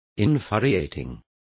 Complete with pronunciation of the translation of infuriating.